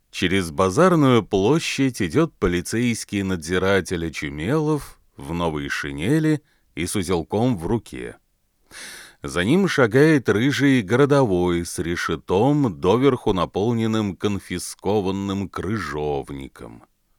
Каких-то лишних шумов, фона нет, но есть довольно резкий срез начиная примерно с 600 Гц.
Конечно, не идеально, но и уже не "телефон".